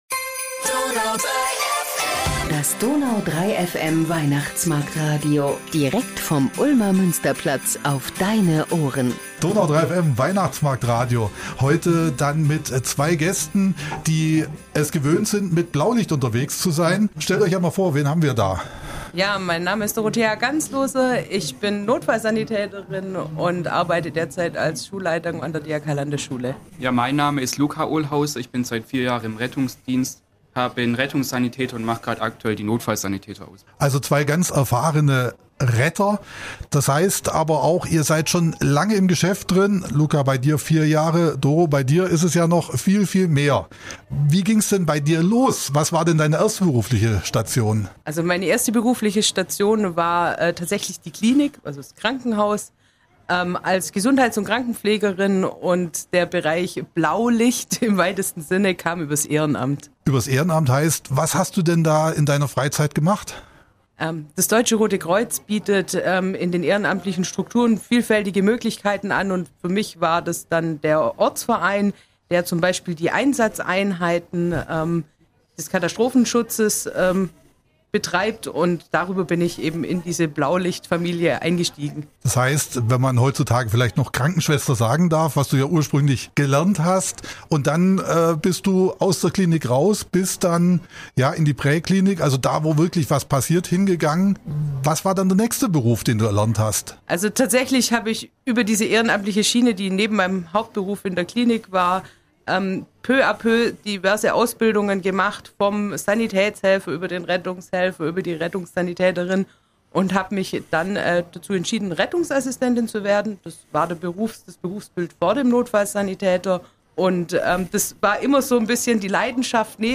Im DONAU 3 FM Weihnachtsmarktstudio geben sie uns ein paar Einblicke in ihren Beruf und erzählen auch, wie gerne sie anderen Menschen helfen.